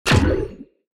Simulation, Building, Game Menu, Ui Tab Switch Sound Effect Download | Gfx Sounds
Simulation-building-game-menu-ui-tab-switch.mp3